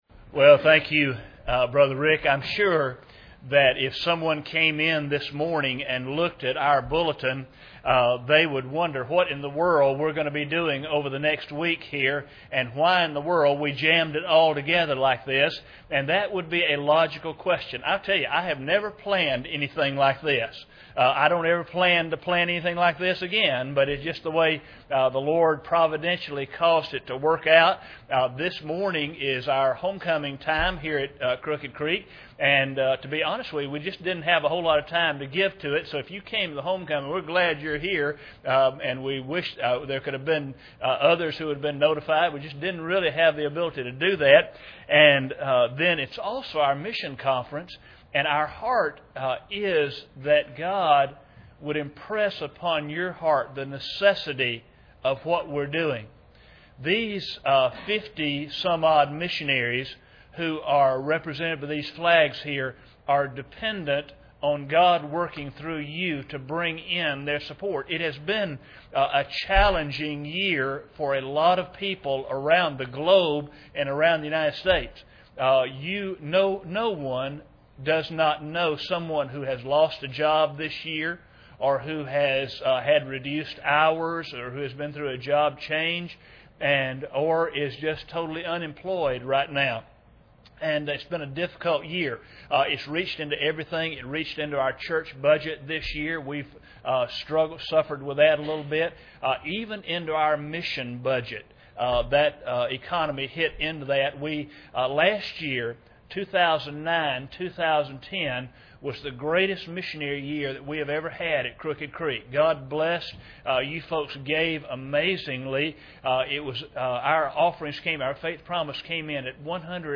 Series: 2011 Missions Conference
Service Type: Sunday Morning